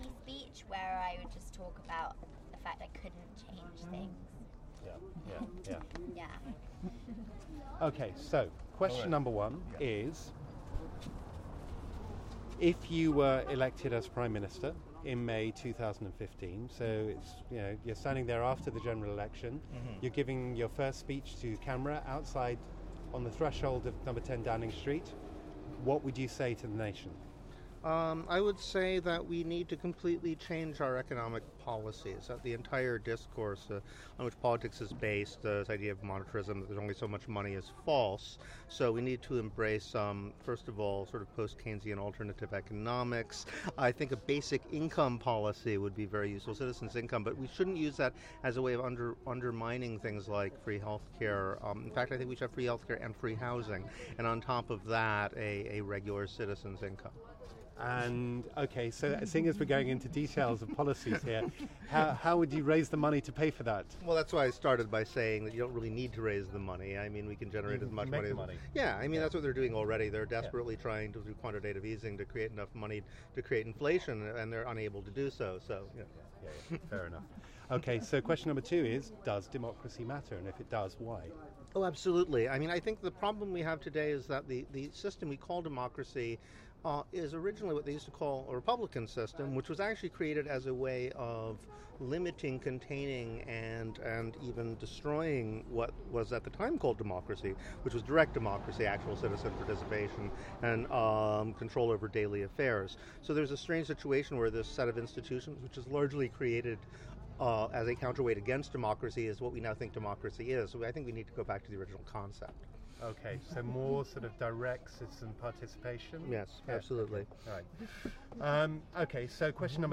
David Graeber - author and anthropologist and one of the founders of the Occupy movement...
Interviews with the general public to answer three simple questions about democracy. Part of a public art project for InTRANSIT festival 2014, supported by Royal Borough of Kensington & Chelsea.